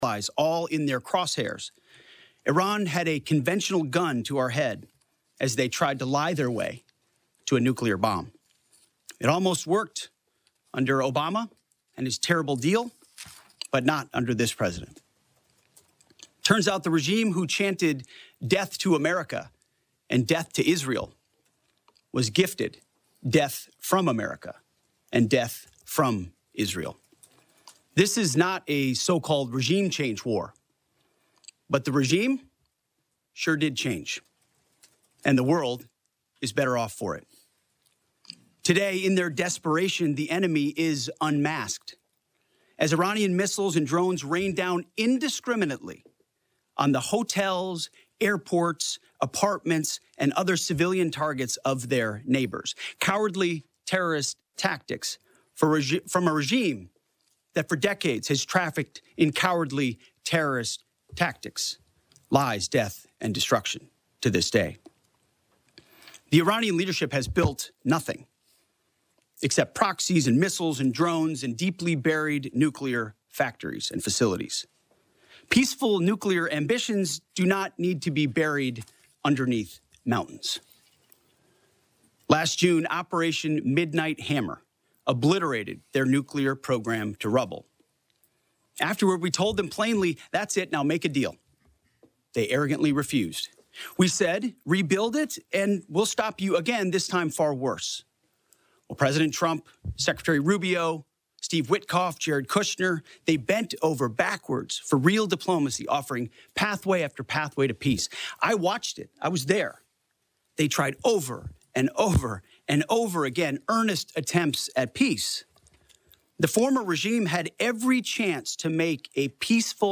Dept. of War Press Conference